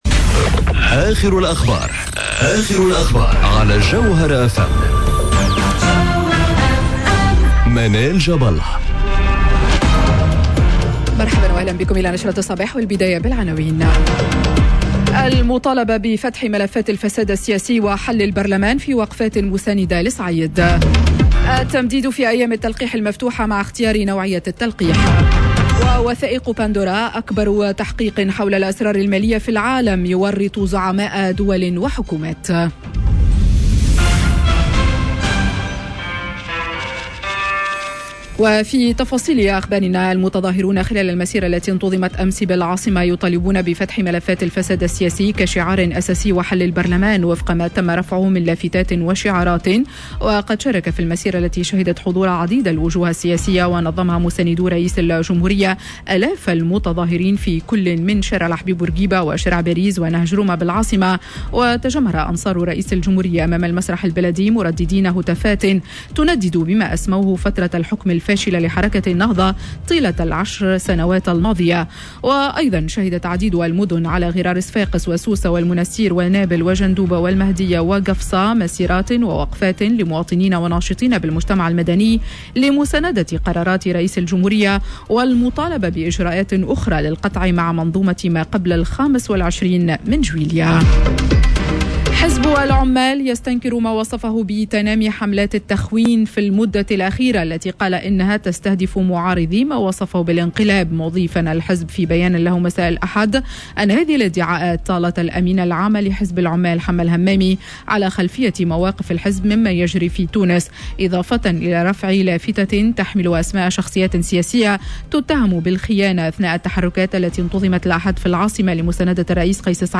نشرة أخبار السابعة صباحا ليوم الإثنين 04 أكتوبر 2021